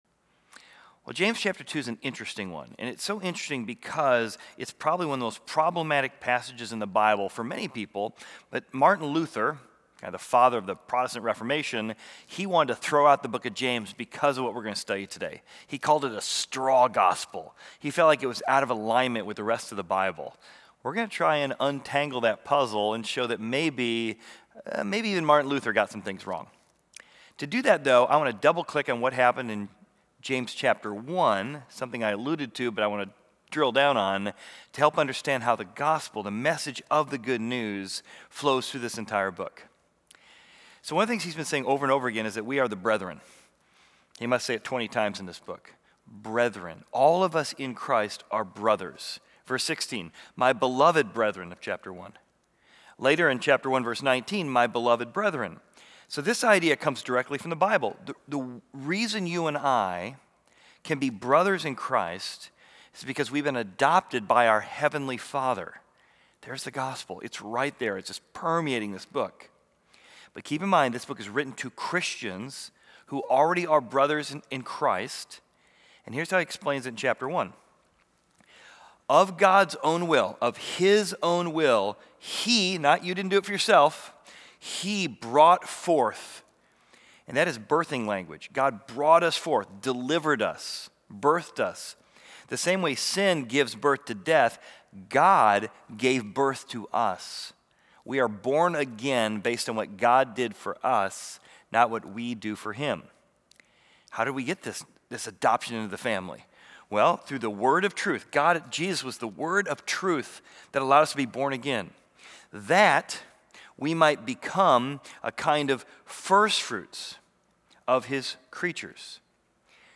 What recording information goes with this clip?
Equipping Service / James: Live and Love Wisely / Active Faith